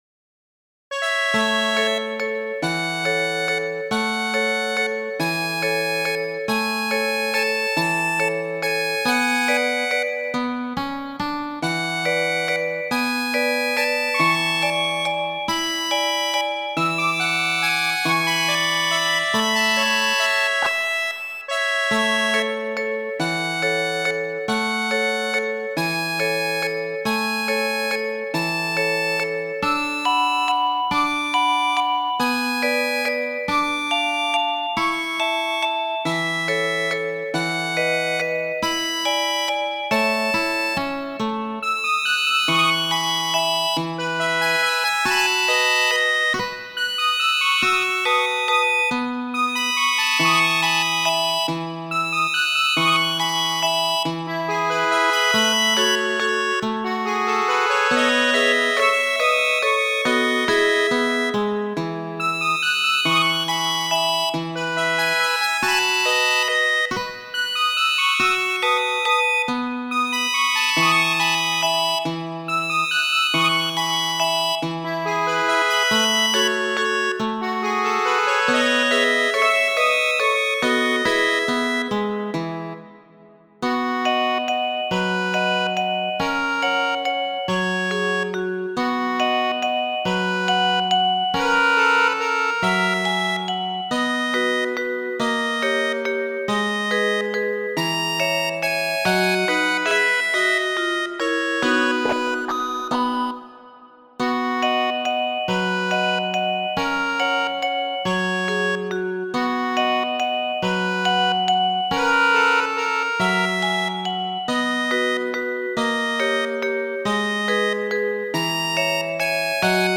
Kaj krome ni salutas vin valse...
Granda valzo, originale por gitaro de Francisco Tárrega, en aparta versio de mi mem.